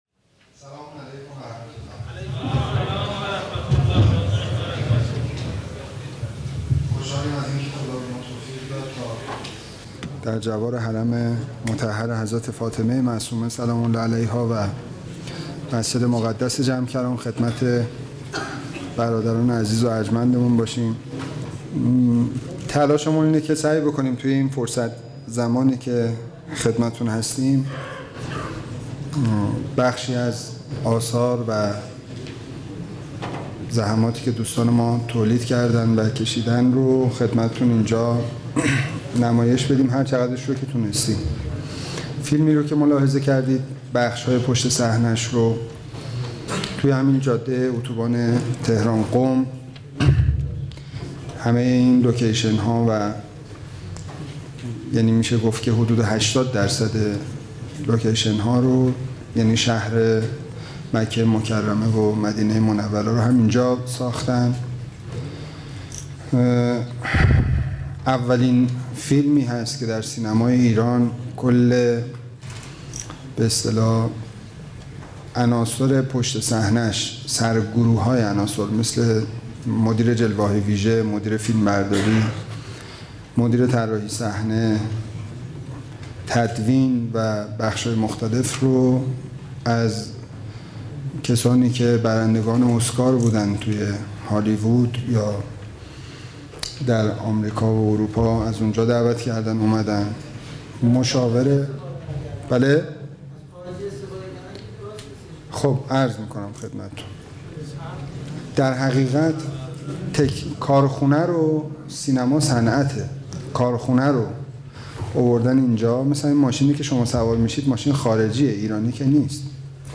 سخنرانی
در دومین همایش هیأت های محوری و برگزیده کشور در اسفندماه 1392